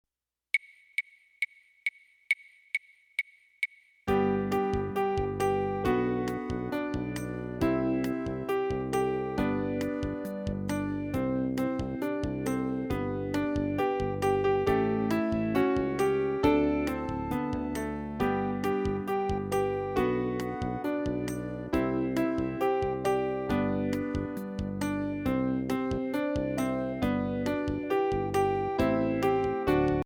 Voicing: Piano/Vocal